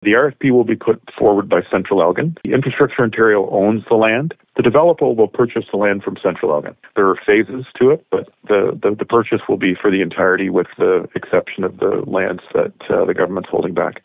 Sloan stresses the land will not be parceled off but, instead, there will be one winning bid.